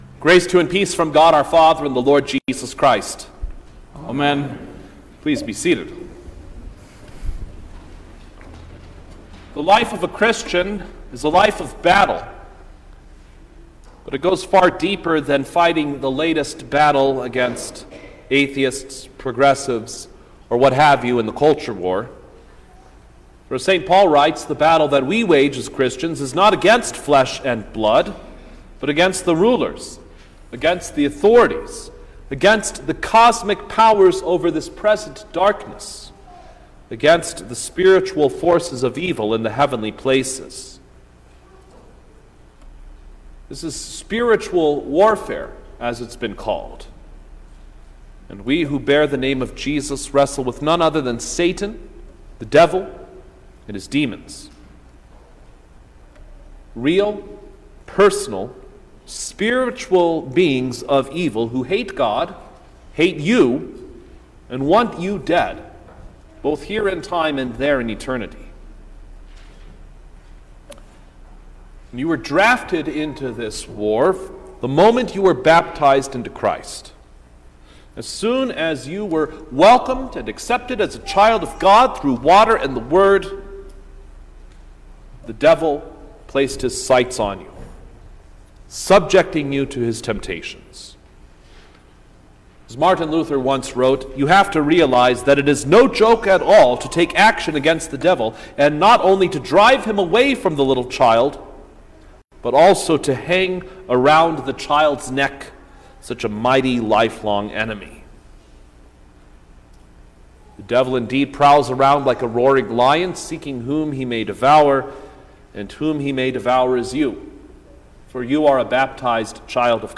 March-6_2022_First-Sunday-in-Lent_Sermon-Stereo.mp3